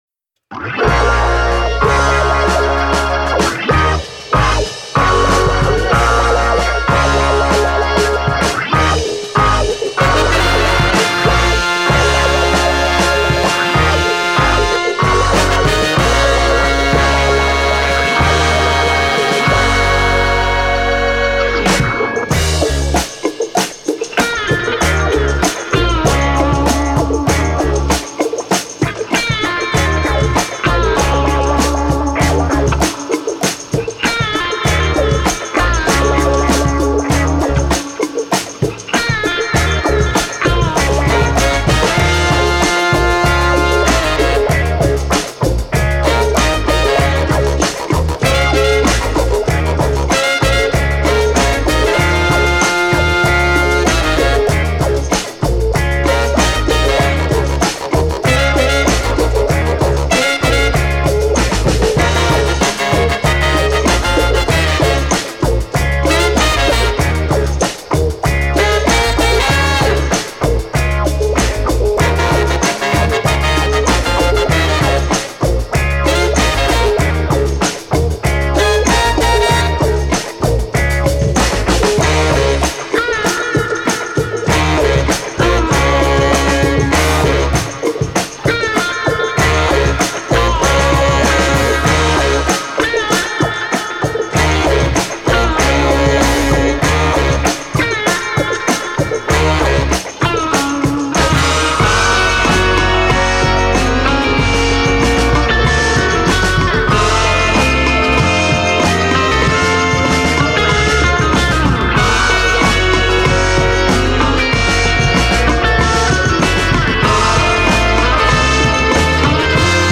Genre: Funk, Jazz Funk